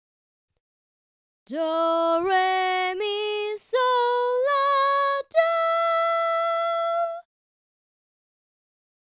Solfa Scale for Melody 5
Ex-5c-solfa-scale.mp3